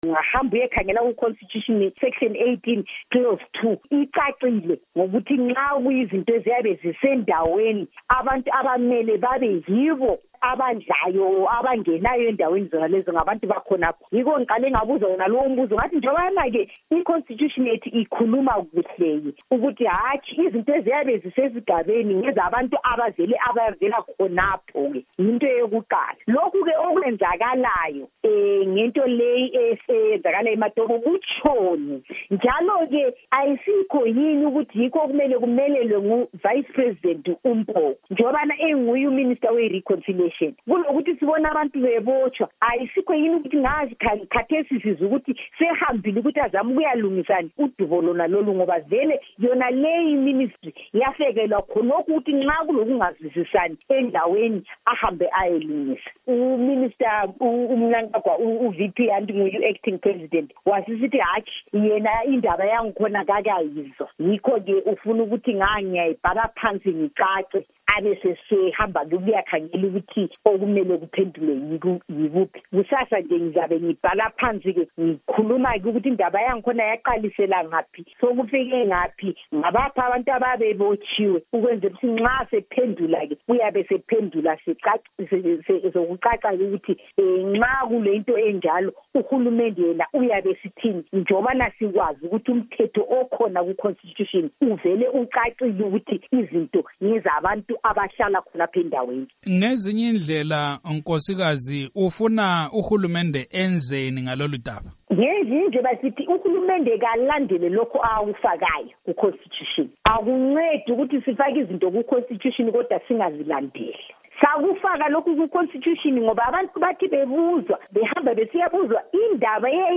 Ingxoxo loNkosikazi Priscillar MIsihairambwi-Mushonga